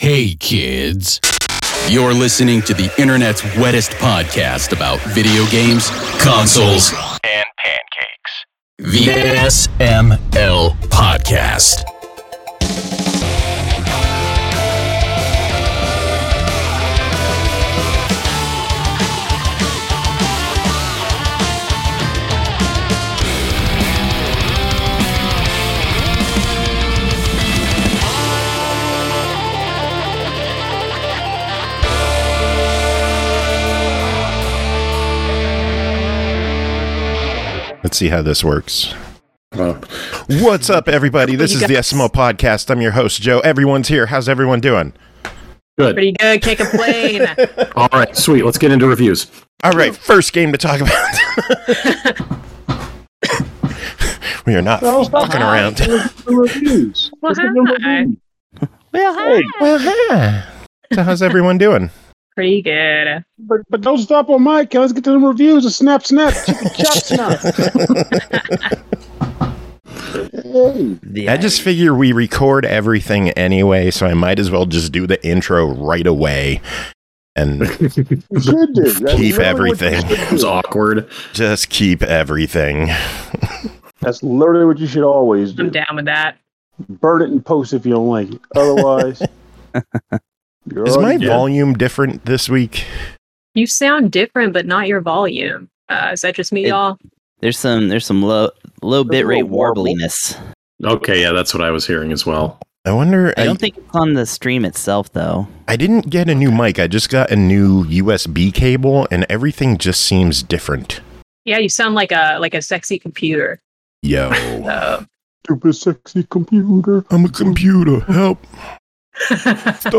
It’s another PartyCast and this time we kick things off with AUDIO ISSUES!